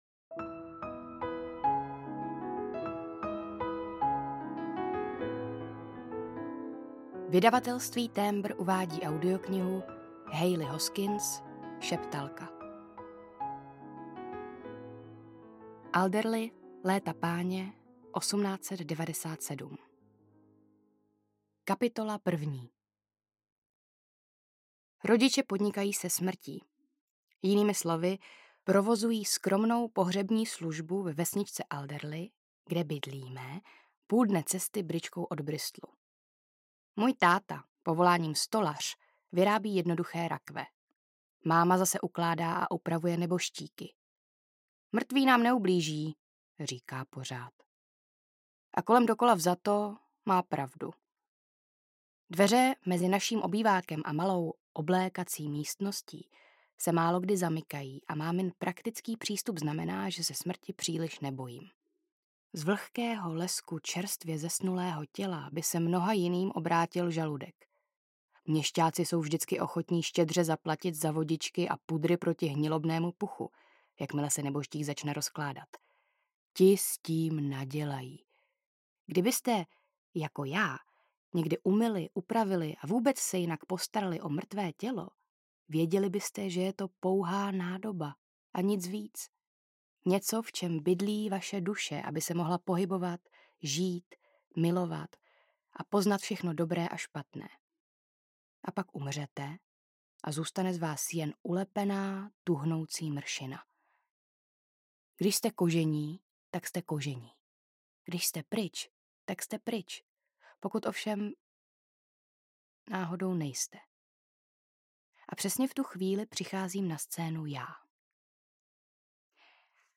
Šeptalka audiokniha
Ukázka z knihy
septalka-audiokniha